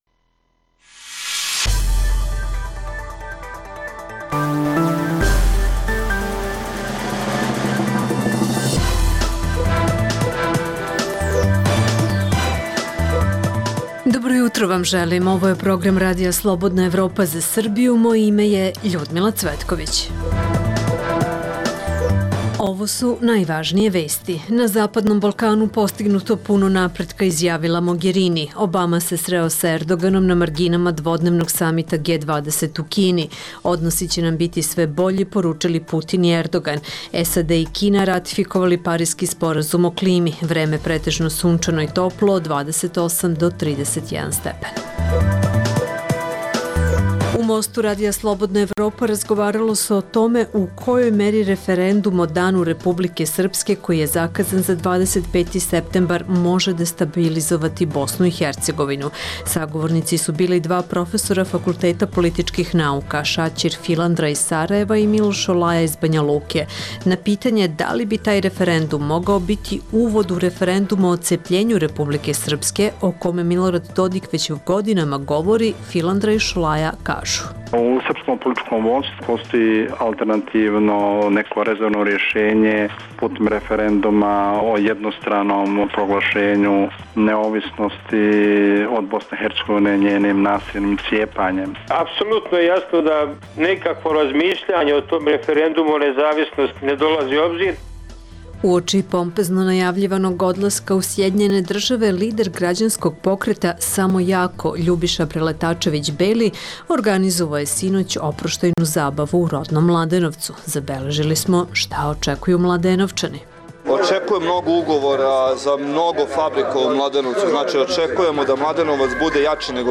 Čućete i atmosferu sa oproštajne zabave lidera građanskog pokreta "Samo jako", Ljubiše Preletačevića Belog uoči odlaska u SAD.